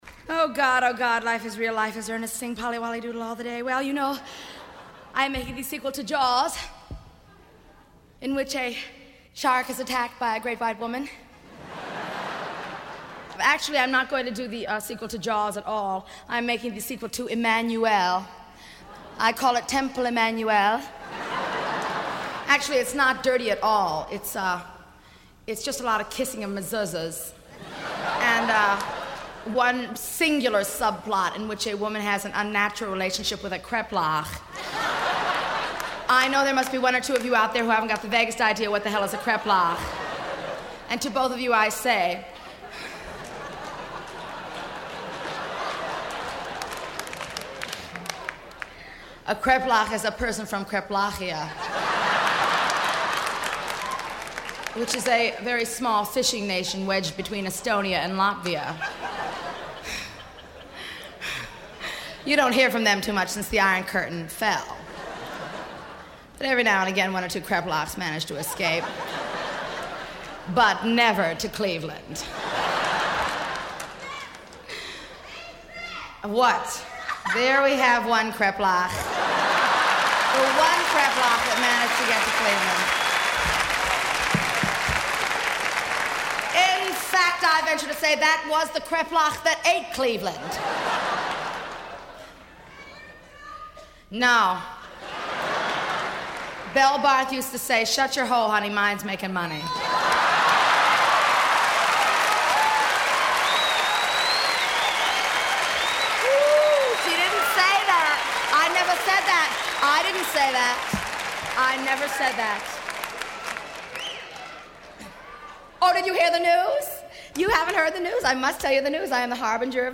05 Comic Relief (Live)
05-Comic-Relief-Live.mp3